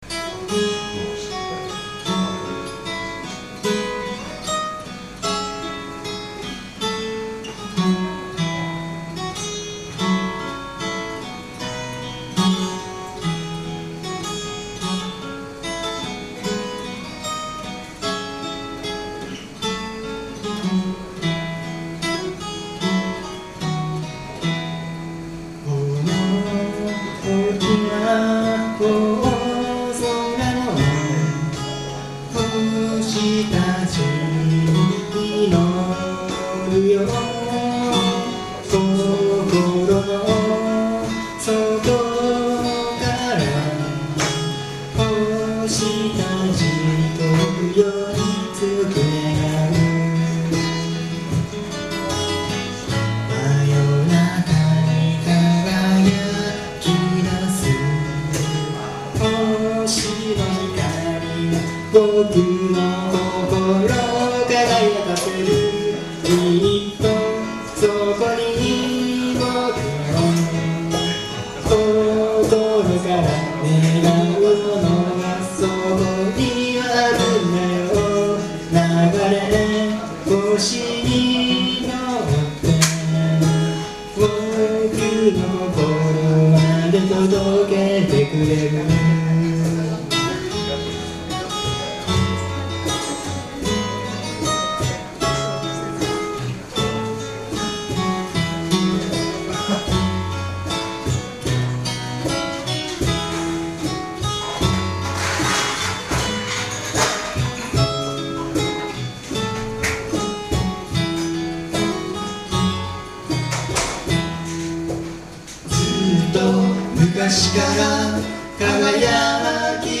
Bluegrass style Folk group
Key of E→F#
しっとりとした曲調と歌詞のイメージから、最近はエンディングテーマにもなっています。
録音場所: 風に吹かれて(大森)
ボーカル、ギター
コーラス、ベース
カホン